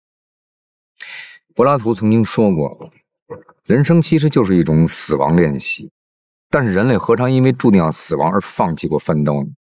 Wciągający Głos Narracji Pierwszoosobowej
Strateg Treści Narracyjnych